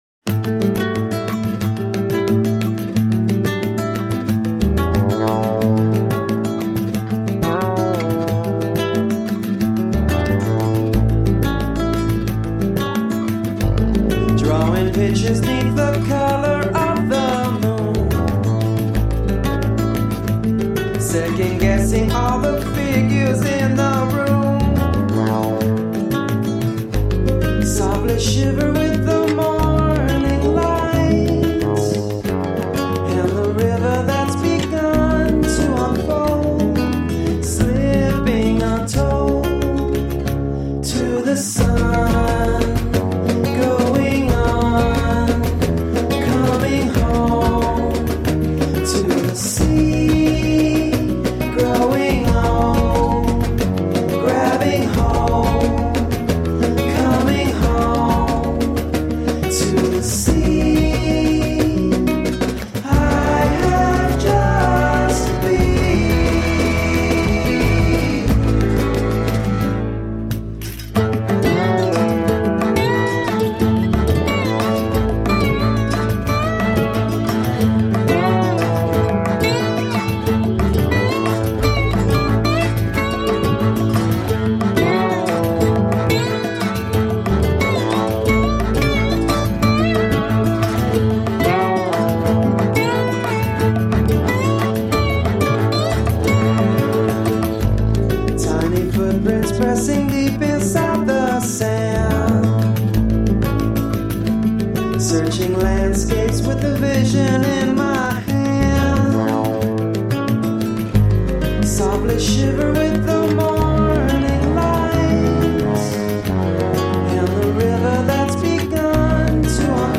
An impressive journey through latin rhythms and moves.
Tagged as: Alt Rock, World